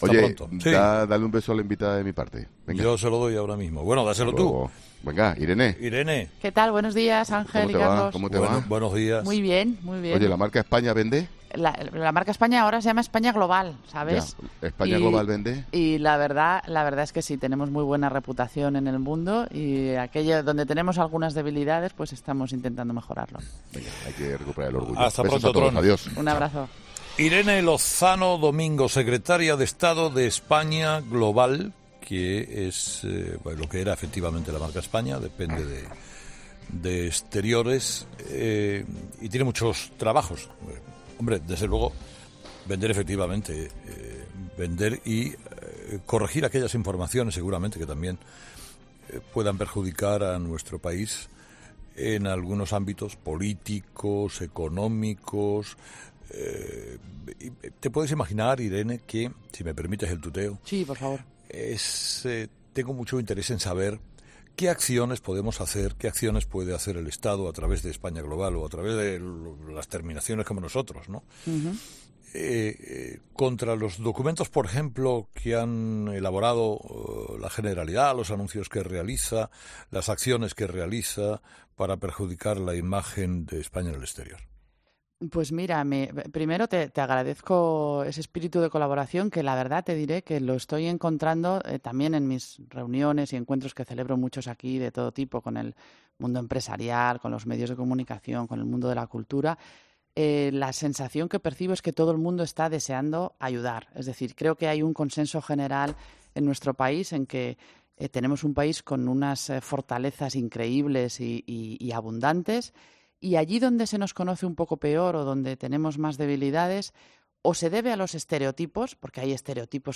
Entrevista con Irene Lozano
En una entrevista este jueves en "Herrera en COPE", Lozano considera que el presidente del gobierno en funciones no quiere que los españoles acudan de nuevo a las urnas "pero es consciente de su responsabilidad y tiene que sacar al país del bloqueo político".